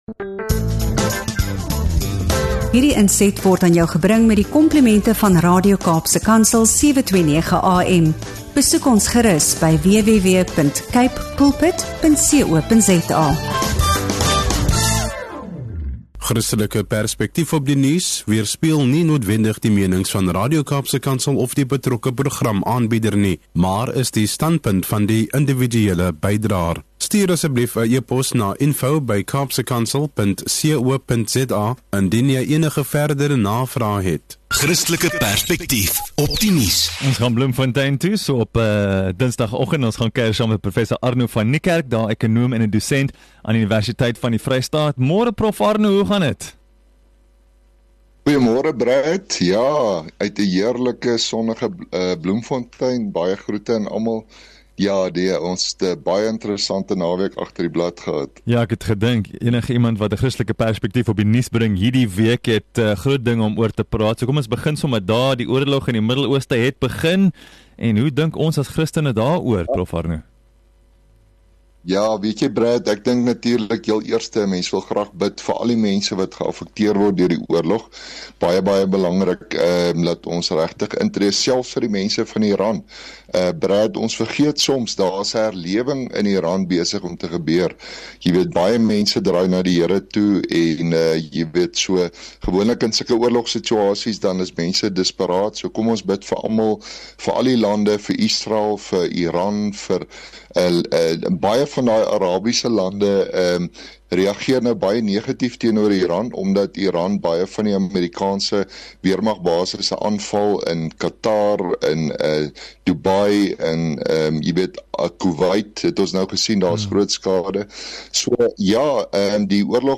diepgaande gesprek